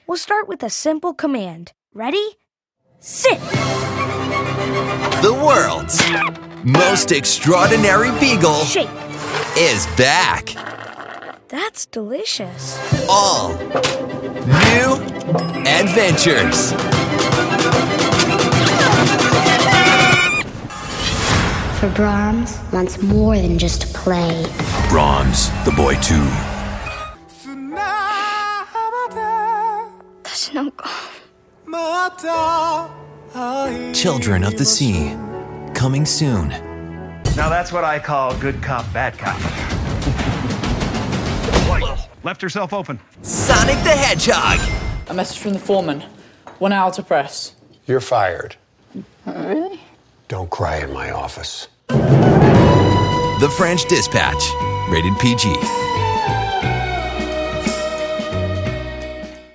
Male
Movie Trailers
Selection Of Trailers
Words that describe my voice are Australian Accent, Versatile, Professional.